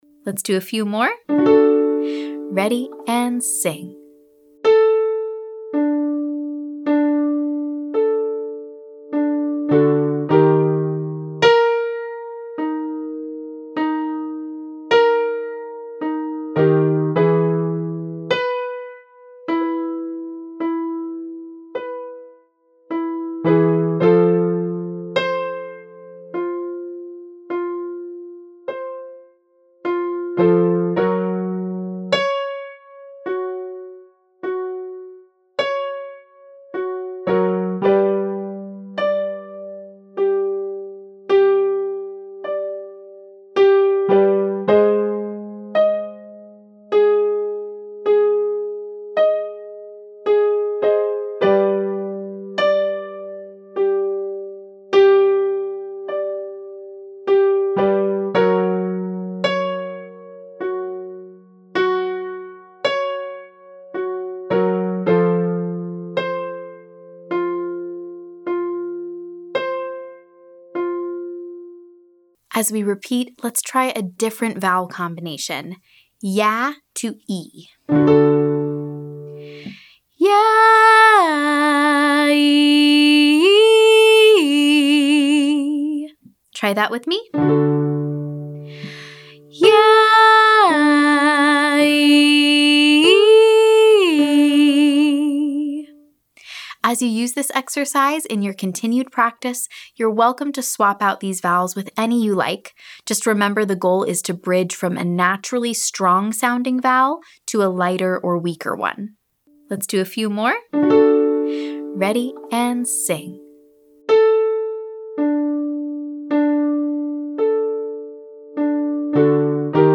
Vowel Choice & Modification - Online Singing Lesson
Vowel Comparison in Chest & Head Voice
Start with a wide vowel like AW and transition to a narrow vowel like OO.
Pattern: YAW 51, OO 151
Demonstration: Speak and sing the adjustment, then try in context.